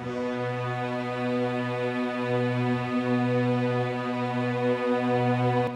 piano-sounds-dev
b4.wav